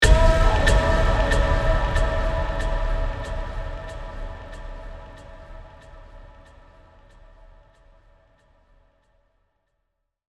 Download Vocal sound effect for free.
Vocal